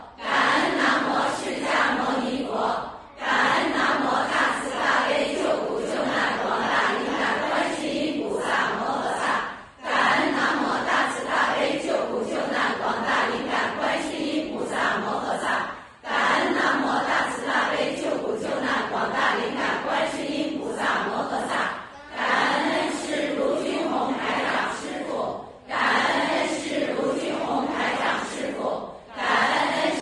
音频：《日本共修会》日本东京观音堂_2021年12月05日！